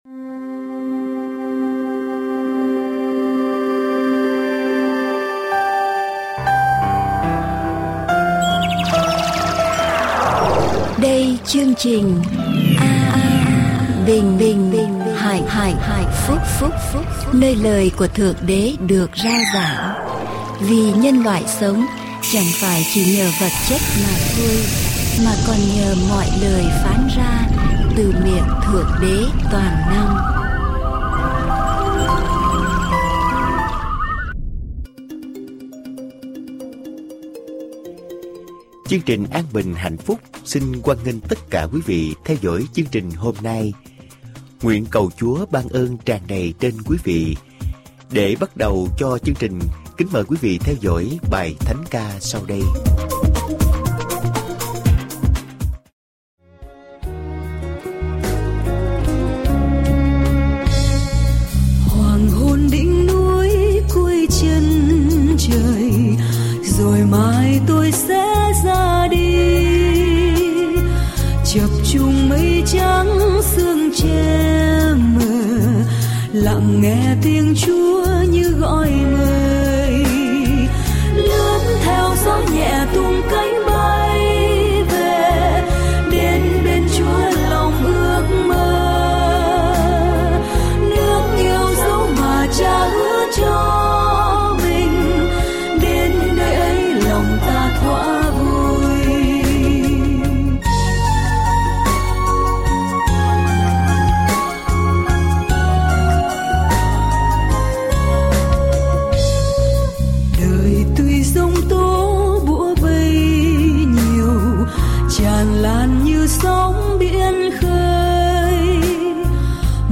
Adventist Vietnamese Sermon